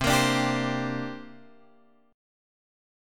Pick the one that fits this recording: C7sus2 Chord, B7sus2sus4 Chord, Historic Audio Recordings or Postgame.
C7sus2 Chord